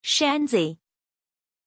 Pronunciation of Shenzi
pronunciation-shenzi-zh.mp3